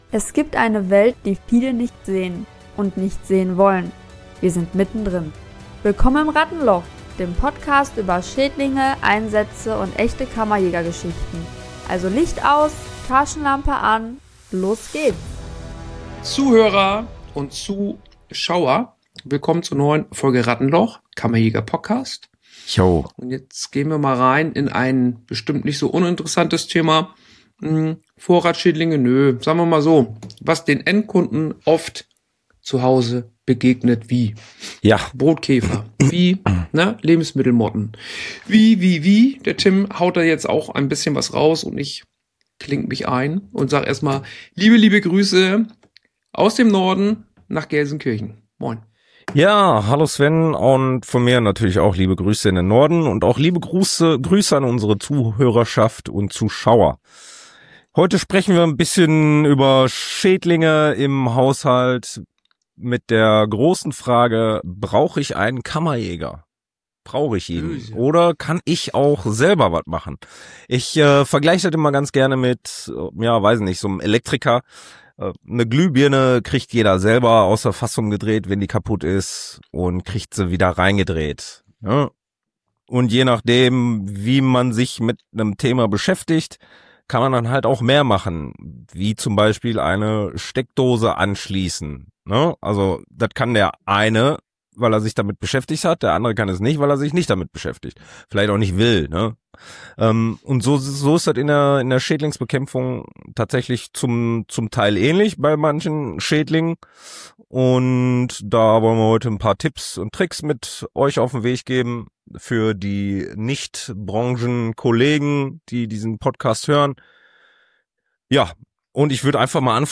Echte Tipps aus dem Alltag von zwei Kammerjägern, die wissen, wovon sie sprechen – ehrlich, direkt und mit dem typischen Rattenloch-Humor!